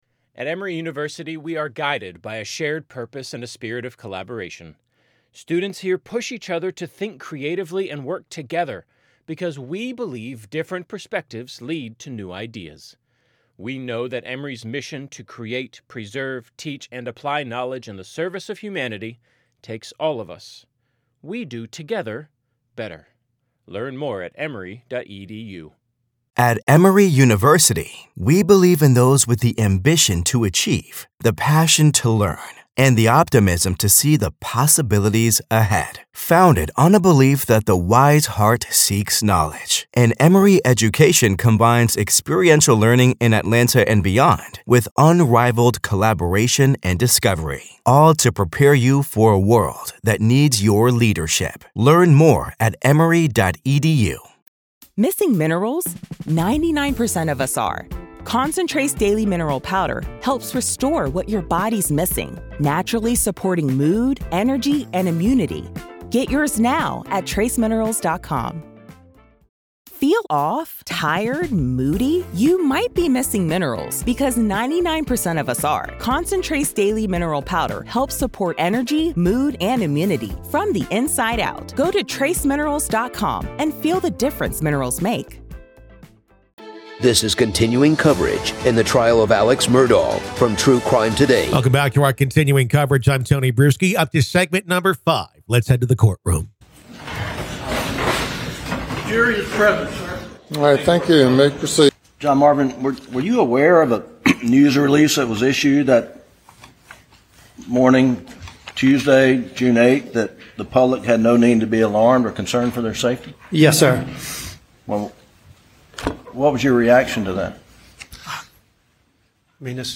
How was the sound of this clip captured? Don't miss a beat of the gripping testimony and explosive evidence as the accused faces life-altering charges for the brutal murder of his own family.